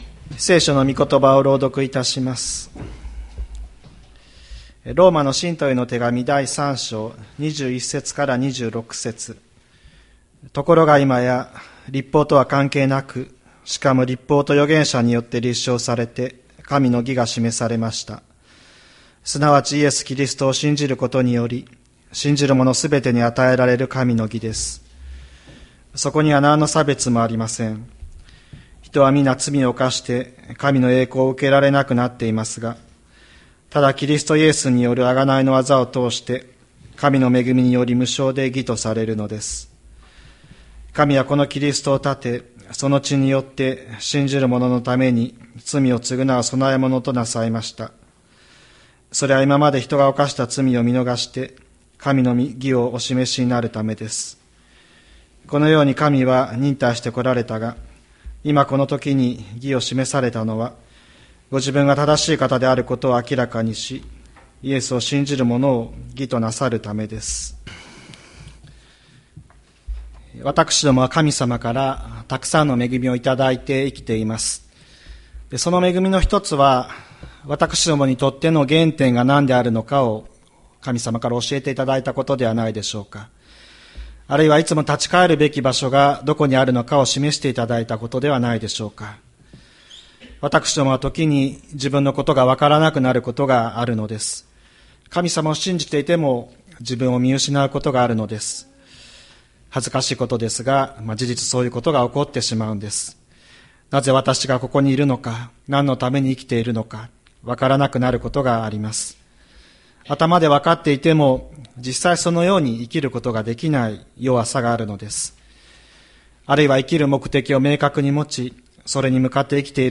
千里山教会 2024年10月27日の礼拝メッセージ。